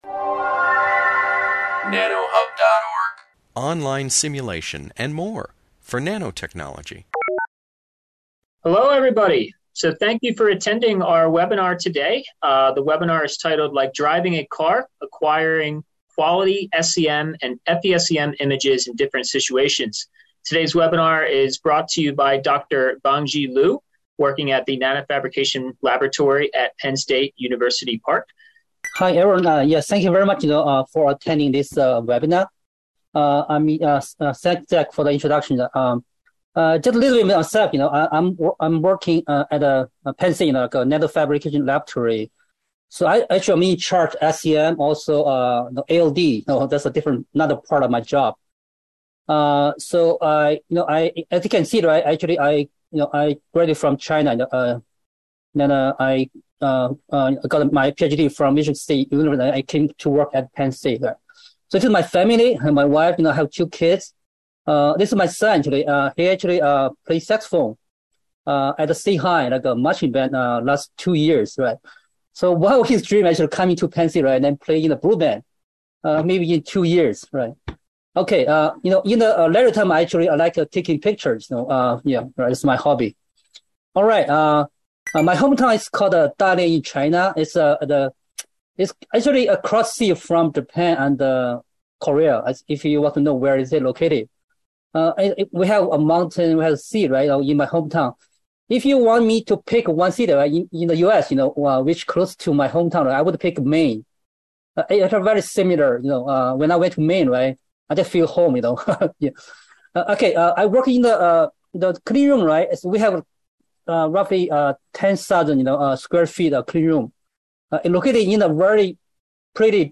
This video presentation provided by the Nanotechnology Applications and Career Knowledge (NACK) Center explores scanning electron microscopy and field emission scanning electron microscopy (SEM/FESEM) image best practices.